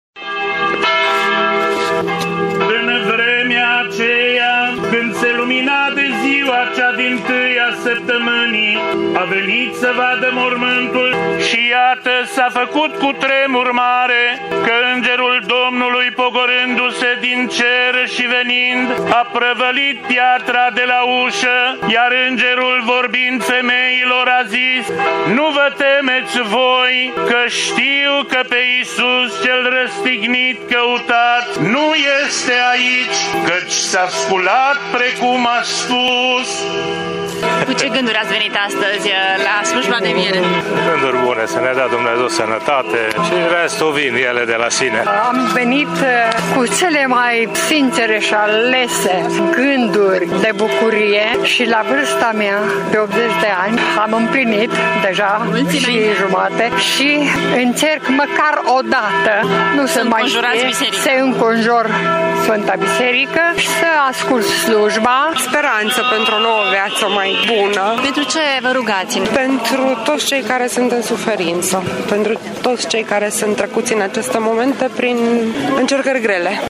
Slujba a fost transmisă în direct de postul nostru de radio de la două biserici din oraș, de la ora 23,00 până la 3,00 dimineața.
Au participat câteva sute de persoane, alături de oficialitățile locale.